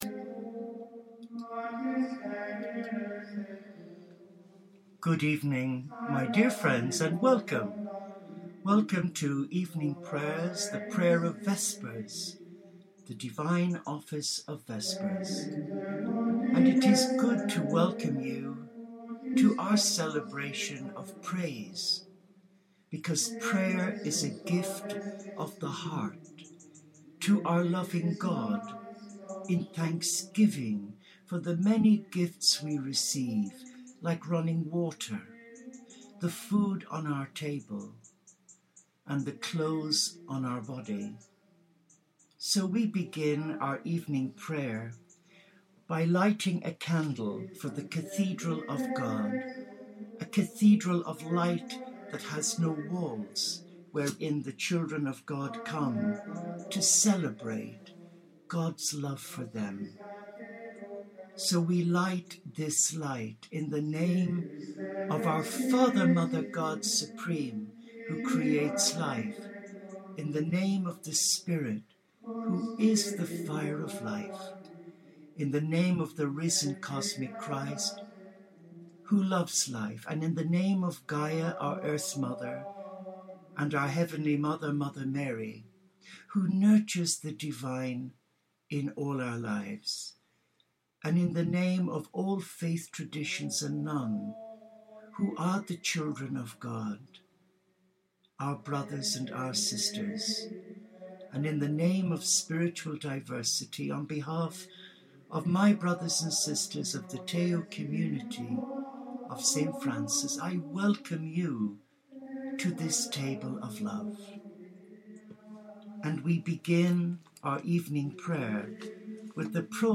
Evening Prayer of Vespers 4 Global Unity & Peace Sat: 6 Sept'14